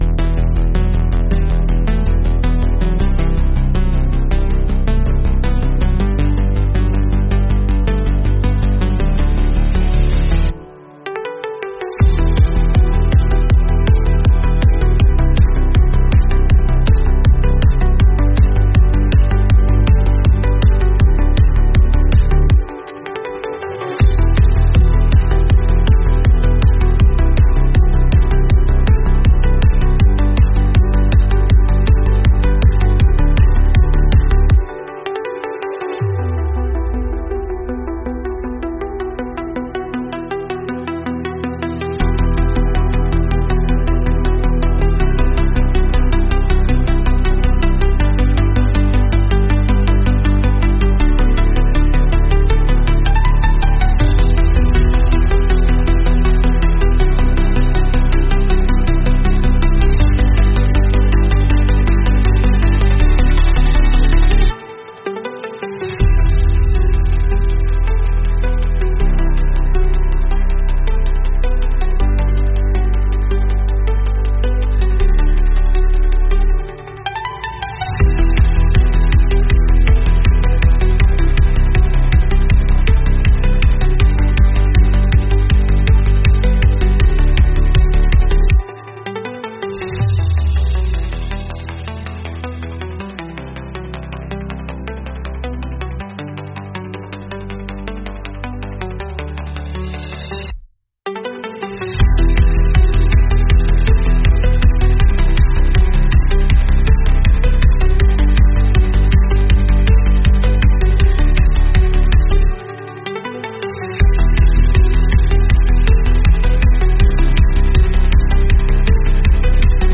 Instrumental -Real Liberty Media DOT xyz